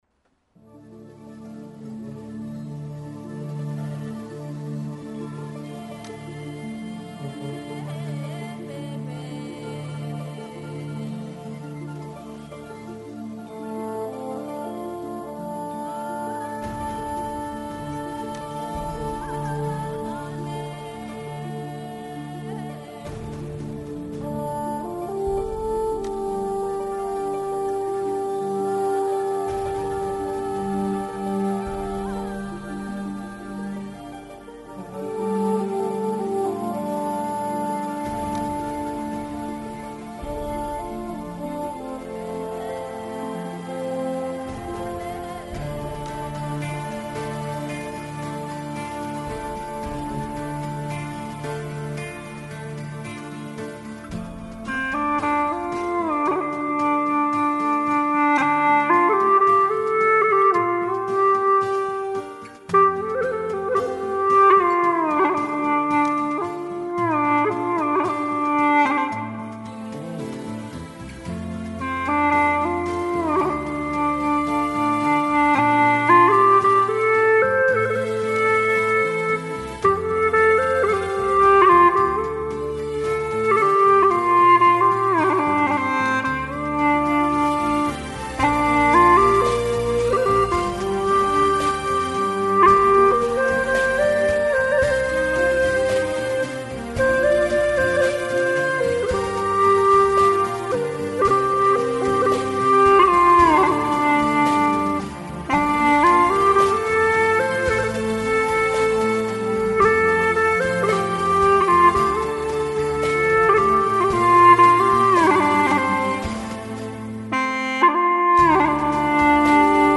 调式 : F 曲类 : 独奏